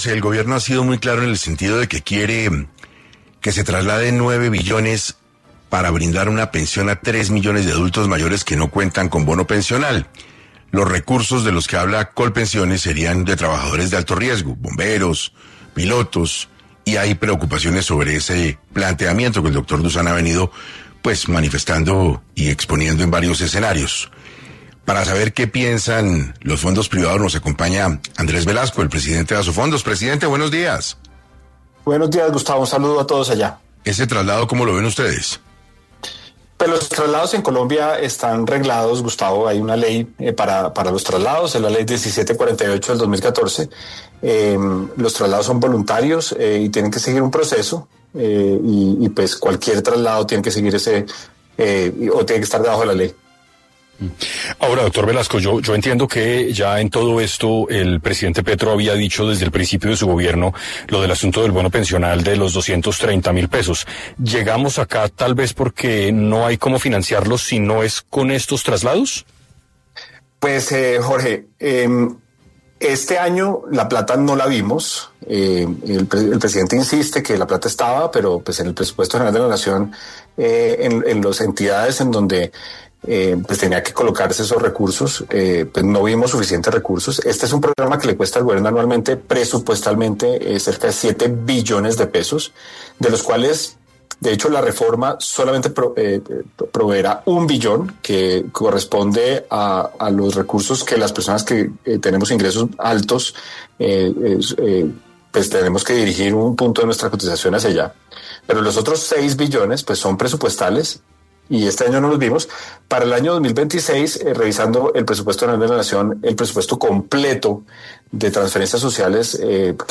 Radio en vivo